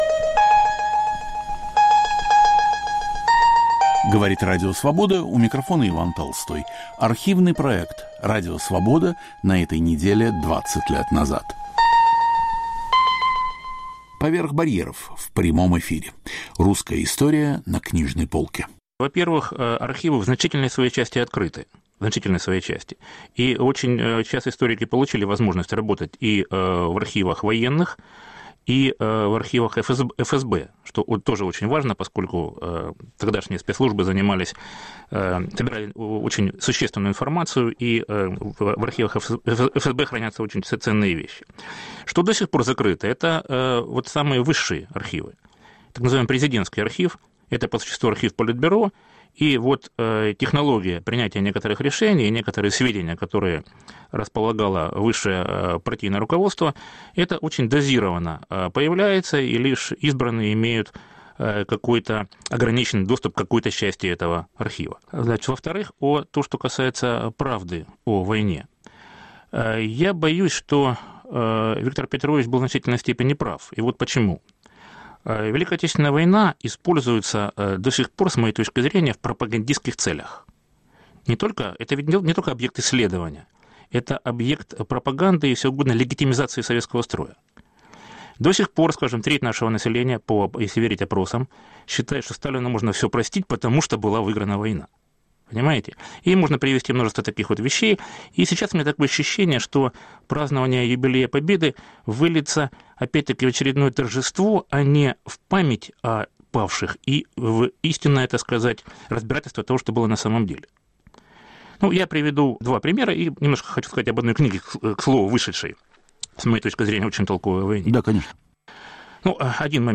"Поверх барьеров" в прямом эфире. Русская история на книжной полке